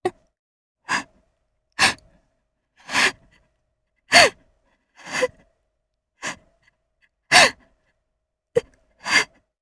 Xerah-Vox_Sad_jp.wav